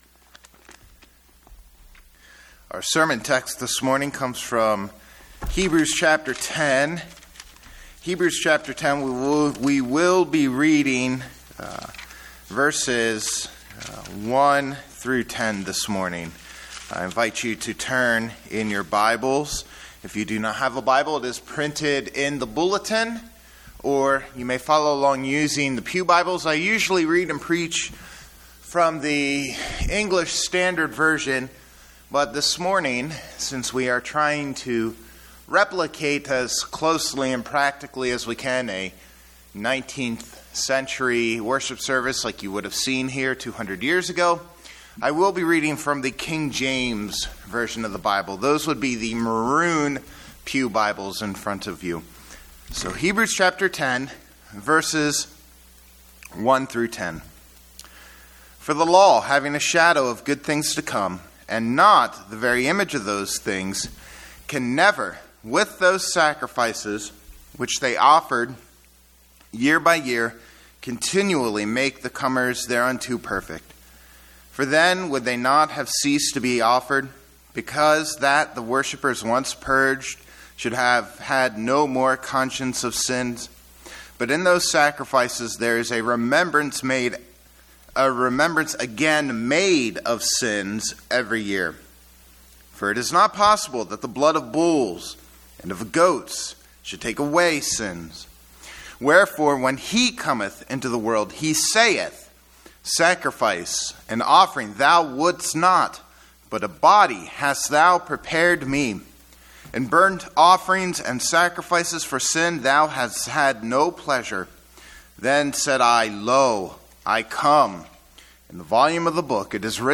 #25 in a series on the Book of Hebrews: Jesus Is Better (Hebrews 10:1-10; Psalm 40) Originally preached at our Bicentennial Anniversary service, March 14, 2019.